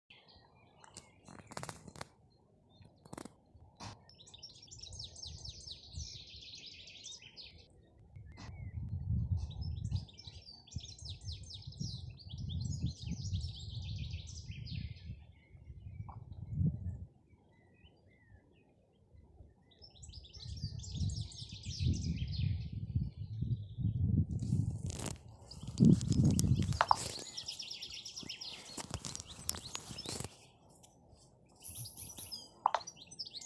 щегол, Carduelis carduelis
СтатусПоёт
ПримечанияMuižas parka kokos